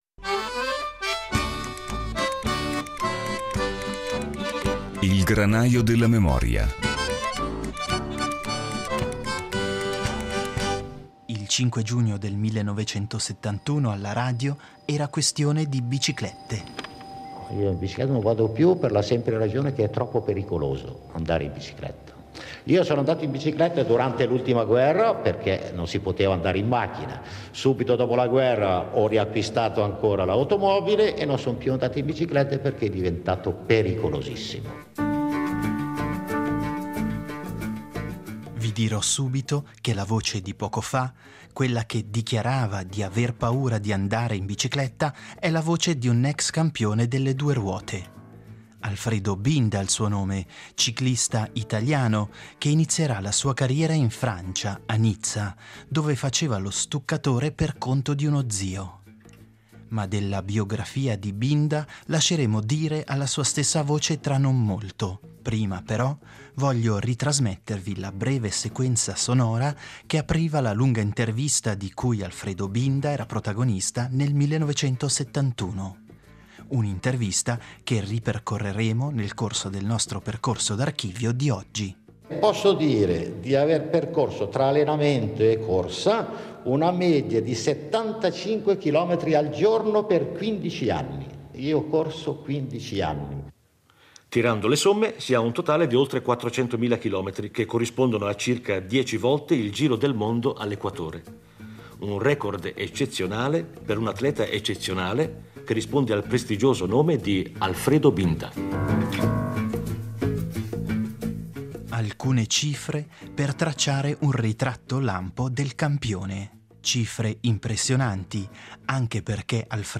Classe 1902, imbattibile in salita, storico rivale di Girardengo, Binda interveniva all’inizio degli anni Settanta ai microfoni della RSI per raccontare sé stesso, il ciclismo e la sua carriera.